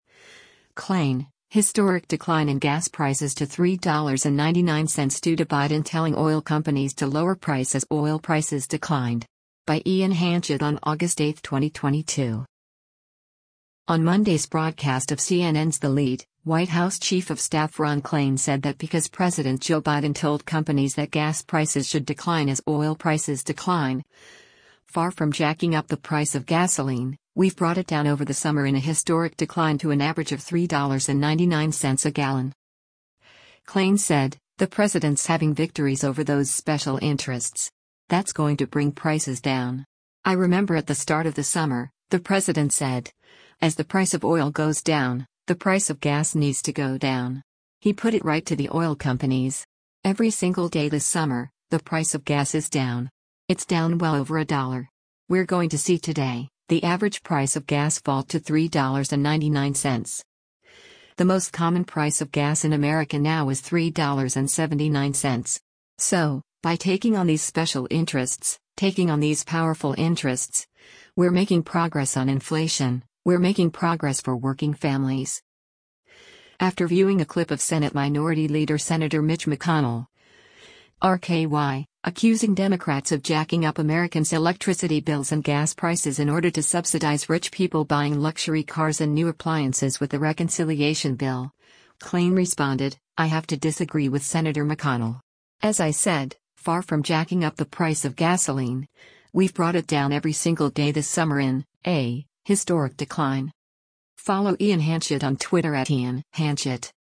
On Monday’s broadcast of CNN’s “The Lead,” White House Chief of Staff Ron Klain said that because President Joe Biden told companies that gas prices should decline as oil prices decline, “far from jacking up the price of gasoline, we’ve brought it down” over the summer in a “historic decline” to an average of $3.99 a gallon.